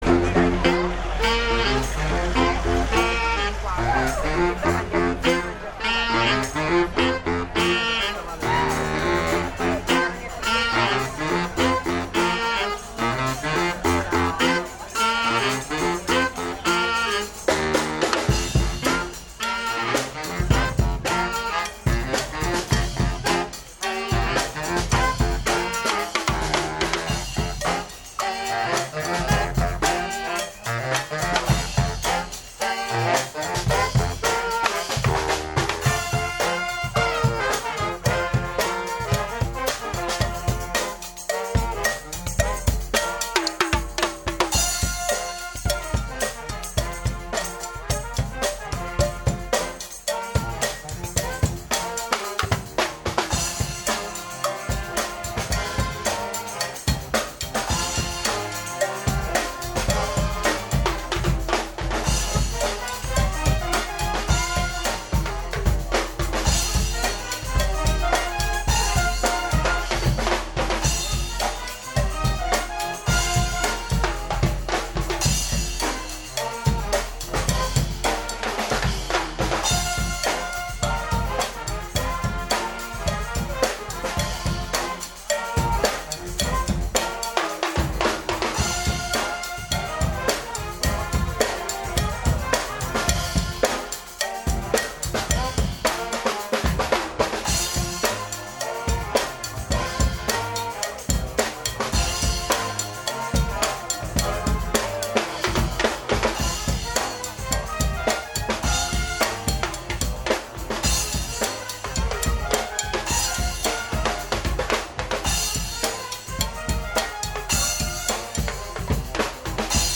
Gambatesa: Raduno Bandistico Del 14 Agosto. Resoconto
Delle bande è stata presa la foto, un breve video perché per ragioni tecniche i pezzi interi non sarebbe stato possibile caricarli e l’audio stereo delle esecuzioni, tutto gratuitamente scaricabile da chi ne vorrà serbare ricordo.